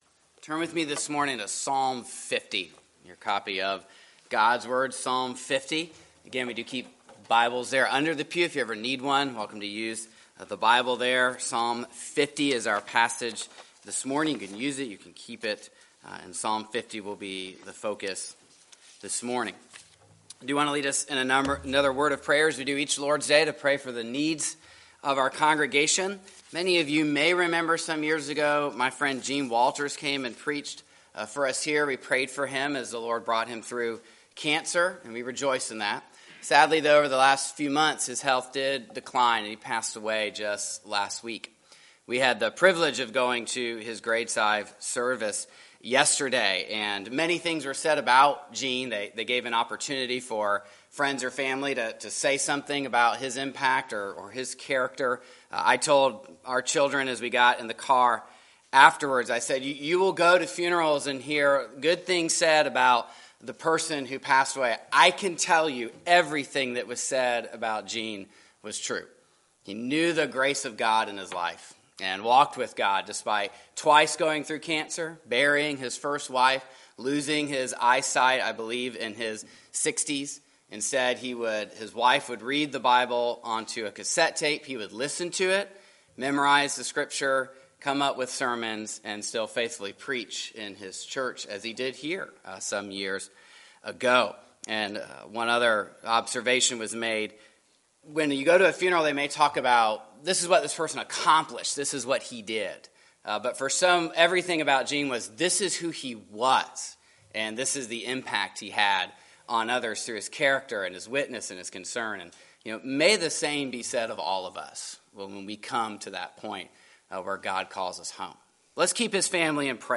Psalm 50:1-23 Service Type: Sunday Morning Psalm 50:1-23 God summons his people to gather before him so that he can evaluate them on their loyalty to his covenant.